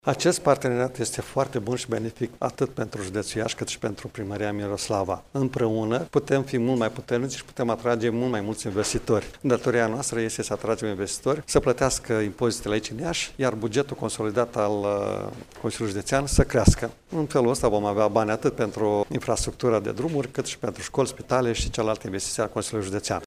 Preşedintele Consiliului Judeţean Iaşi, Maricel Popa, a declarat că această colaborare este necesară deoarece autorităţile judeţene trebuie să se implice în dezvoltarea reţelei de utilităţi din zonă.